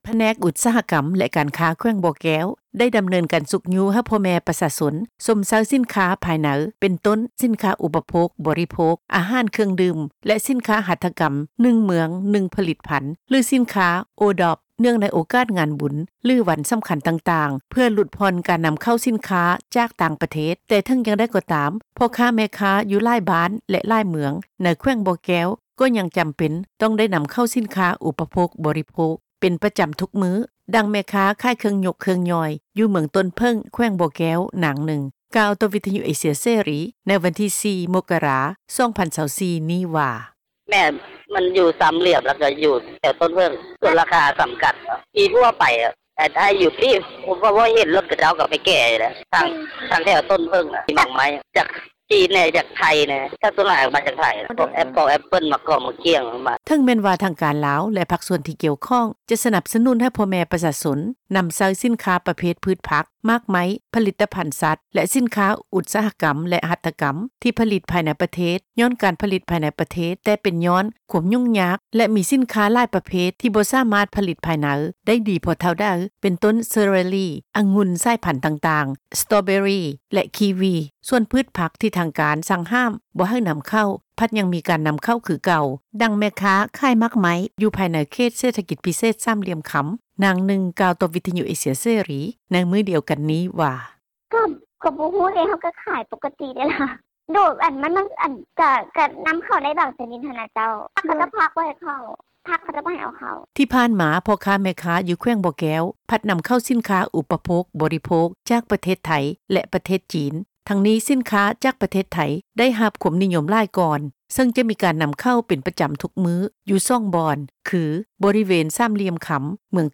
ດັ່ງ ແມ່ຄ້າຂາຍໝາກໄມ້ ຢູ່ພາຍໃນເຂດເສຖກິຈ ພິເສດ ສາມຫລ່ຽມຄໍາ ນາງນຶ່ງ ກ່າວຕໍ່ ວິທຍຸ ເອເຊັຽເສຣີ ໃນມື້ດຽວກັນນີ້ວ່າ:
ດັ່ງ ຄົນງານລາວ ທີ່ເຮັດວຽກຢູ່ພາຍໃນເຂດ ເສຖກິຈ ພິເສດ ສາມຫລ່ຽມຄໍາ ທ່ານນຶ່ງ ກ່າວວ່າ:
ດັ່ງ ແມ່ຄ້າຂາຍພືດຜັກ-ໝາກໄມ້ ທີ່ນໍາເຂົ້າຈາກຕ່າງແຂວງ ແລະ ຕ່າງປະເທດ ນາງນຶ່ງ ກ່າວວ່າ: